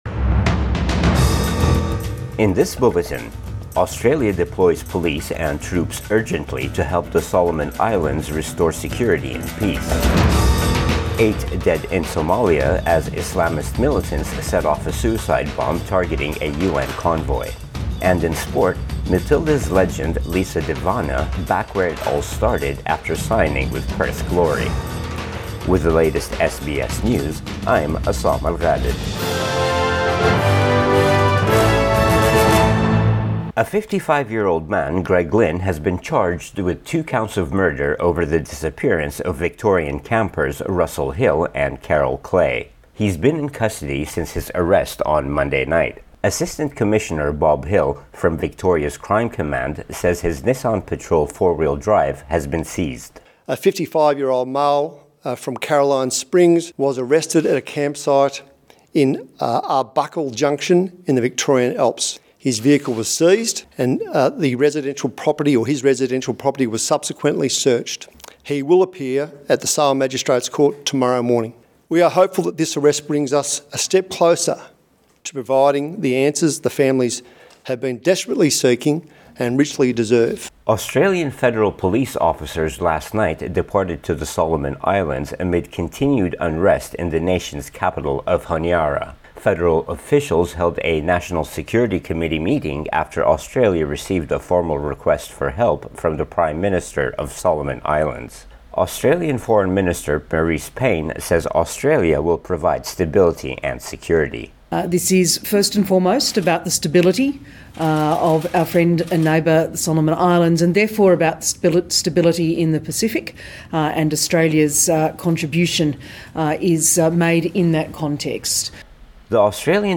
AM bulletin 26 November 2021